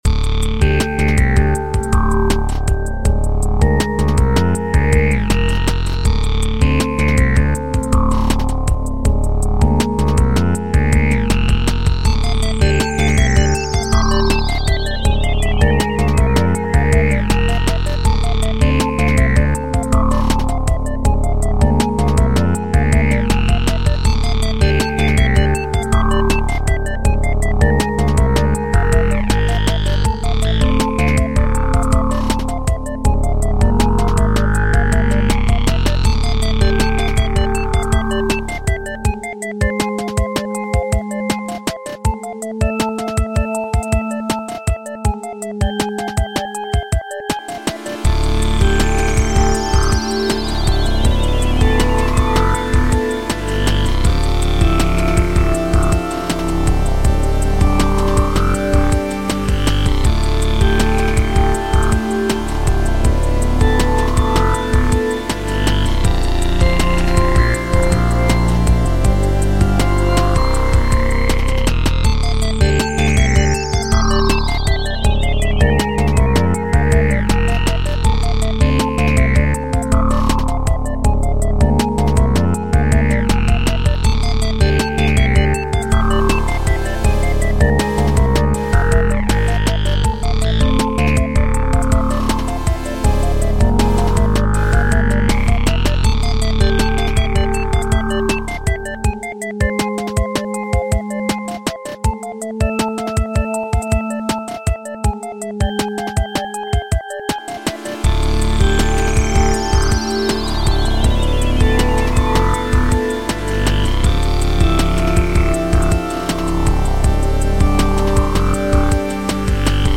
Vintage Warmth, Modern Precision.
Separated neural modeling of vacuum tube & transformer stages — fused with real-time inference and modern digital precision.
This means clean, artifact-free saturation even at lower oversampling rates.